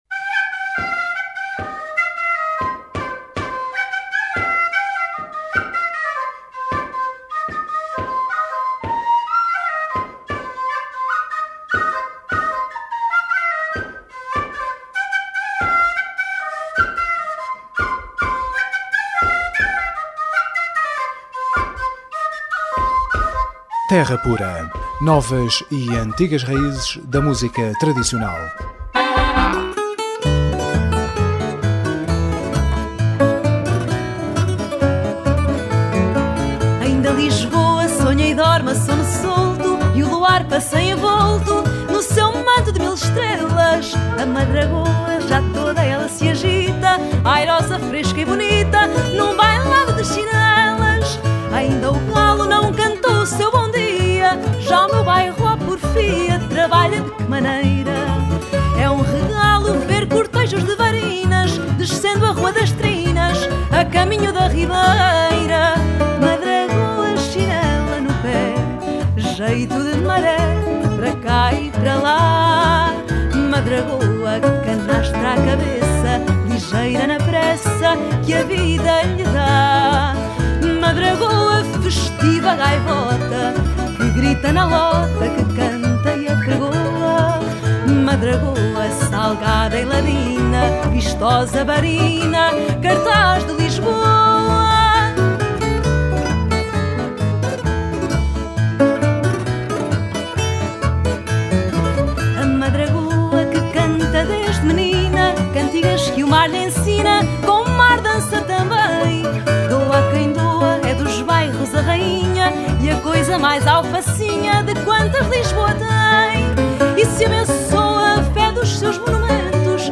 Terra Pura 15ABR13: Entrevista Joana Amendoeira – Crónicas da Terra